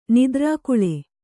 ♪ nidrākuḷe